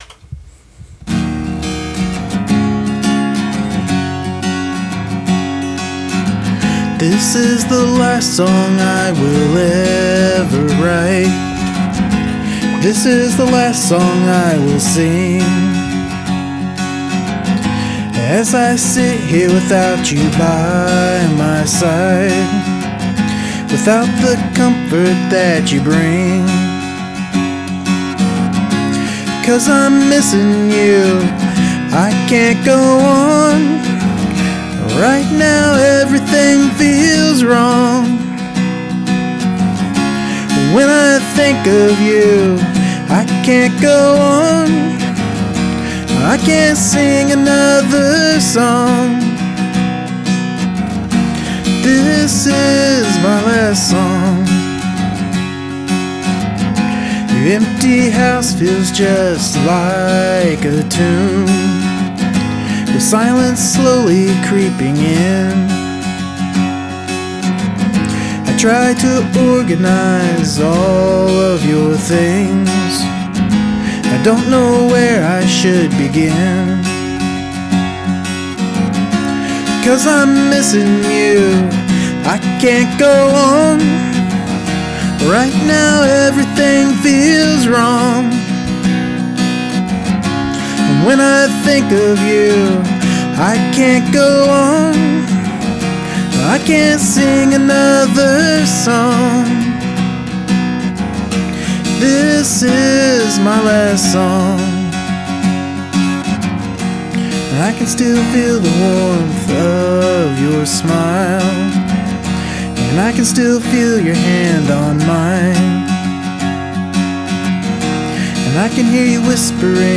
The simple arrangement goes well with this song. Great acoustic solo too.